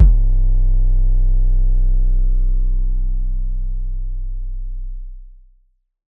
TS 808_8.wav